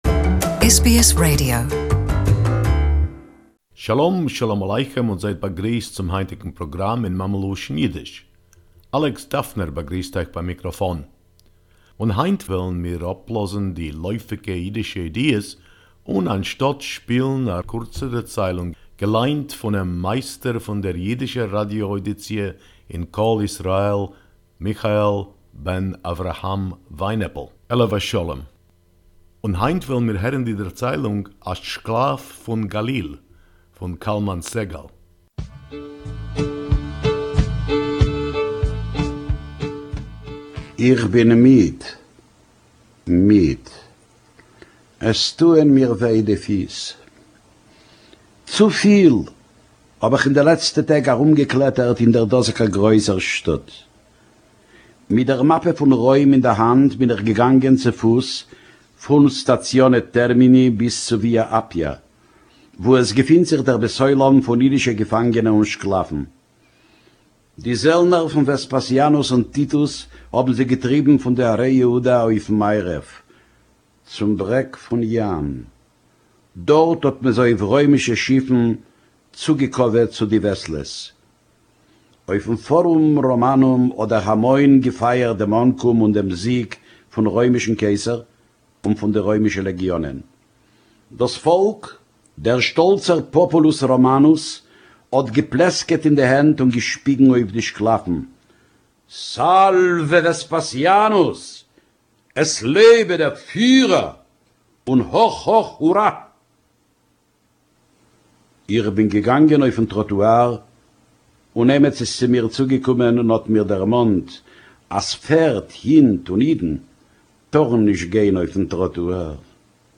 Yiddish story: A Slave From Galilee